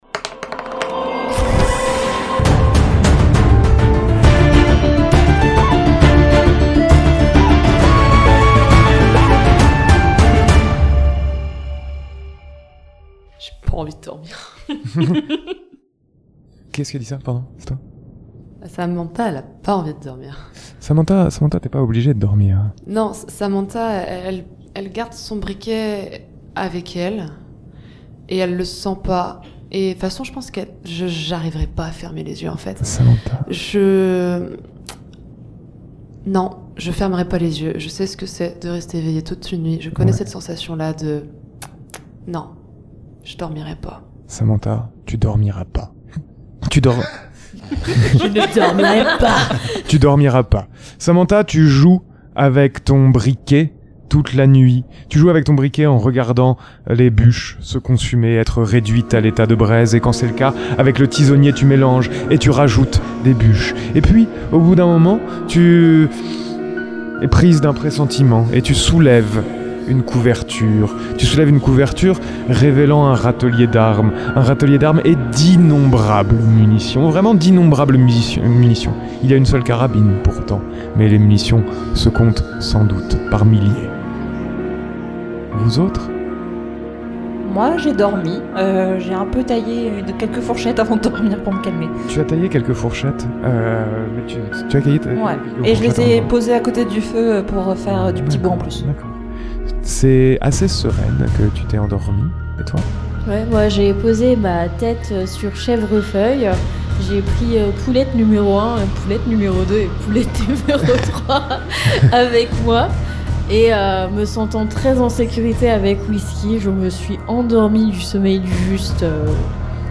Si vous êtes ici c’est pour entendre une partie de JDR horrifique…